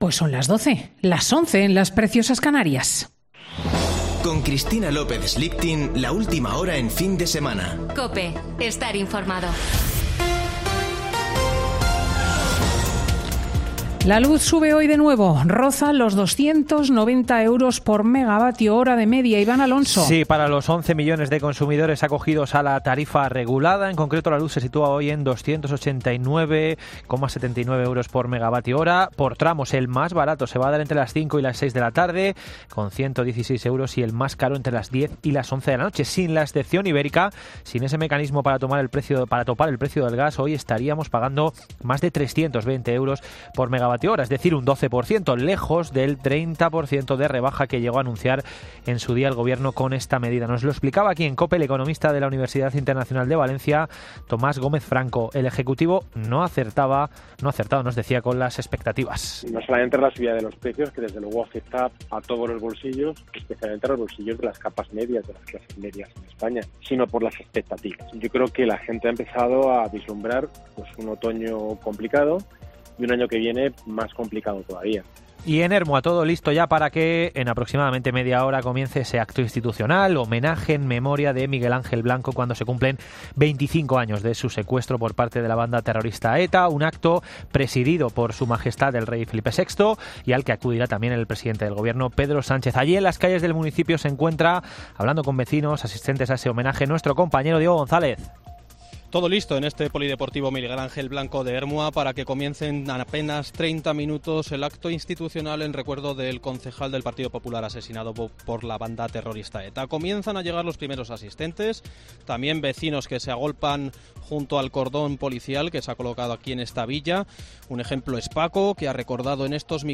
Boletín de noticias de COPE del 10 de julio de 2022 a las 12:00 horas